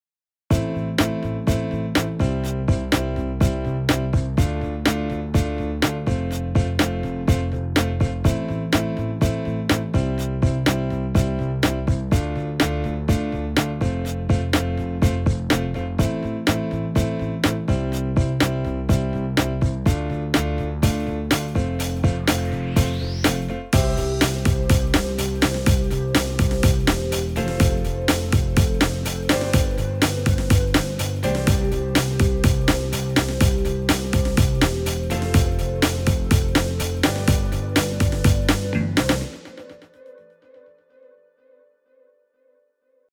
in C karaoke